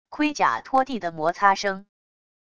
盔甲拖地的摩擦声wav音频